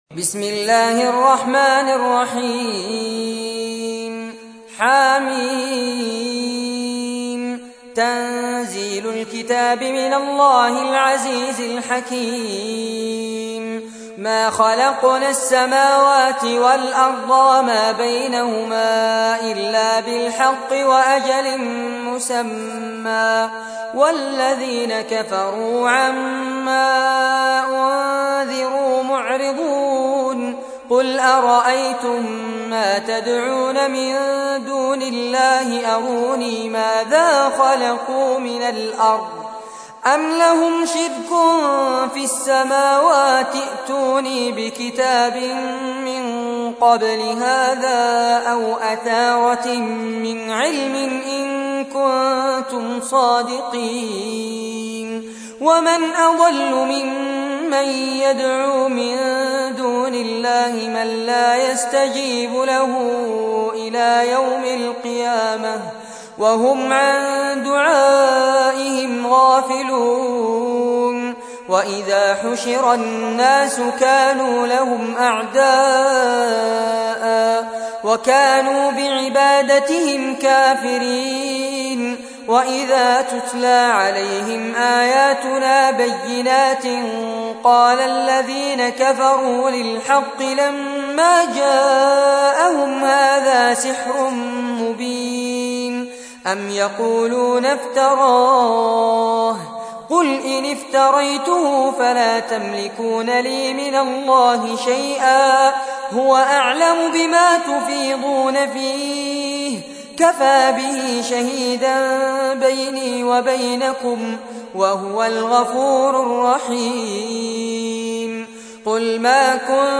تحميل : 46. سورة الأحقاف / القارئ فارس عباد / القرآن الكريم / موقع يا حسين